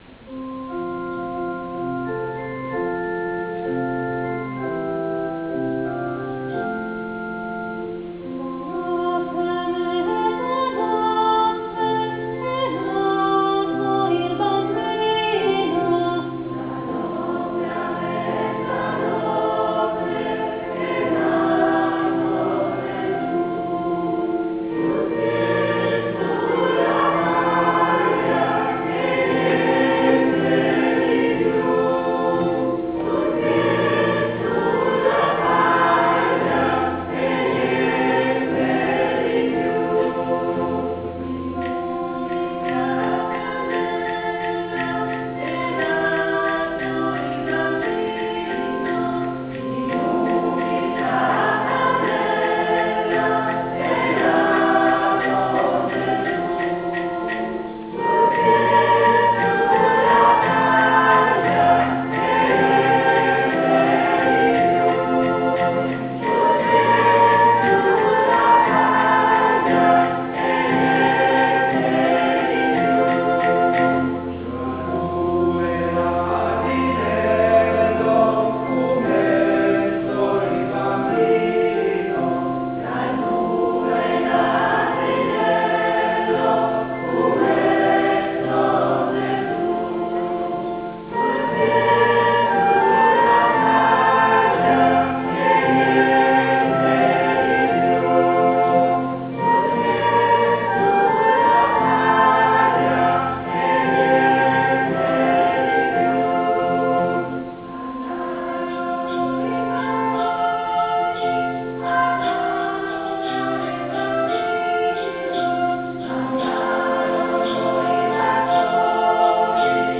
Domenica 19 Dicembre 2010, alla presenza di un folto pubblico, si è svolto a San Faustino il tradizionale  "Concerto di Natale" E' dal 1988 che il "Coro San Faustino" organizza per conto della Parrocchia ed in collaborazione con l'Assessorato alla Cultura del Comune di Rubiera, il "Concerto di Natale."